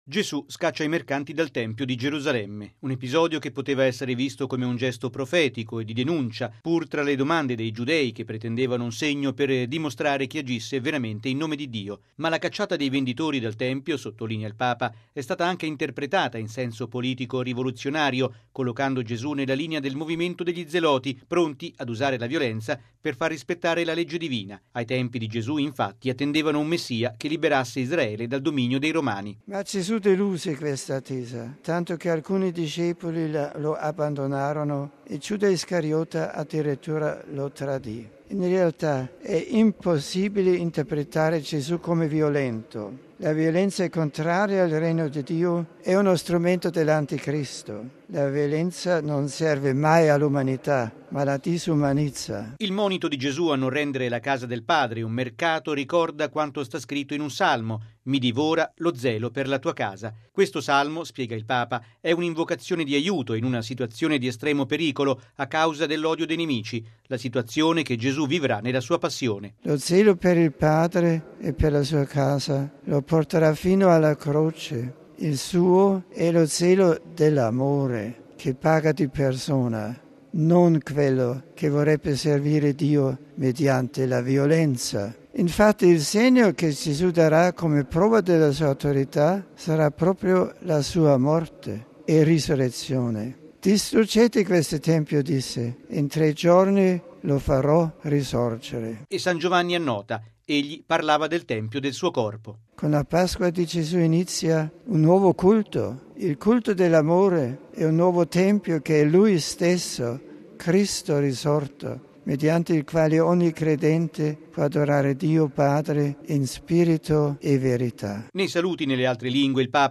◊   “La violenza non serve mai all’umanità, ma la disumanizza”: è quanto ha detto il Papa all’Angelus, commentando il passo evangelico della cacciata dei mercanti dal Tempio, davanti ad alcune migliaia di pellegrini radunati in Piazza San Pietro. Al termine della preghiera mariana, Benedetto XVI ha lanciato un appello per le popolazioni del Madagascar colpite da una devastante tempesta tropicale.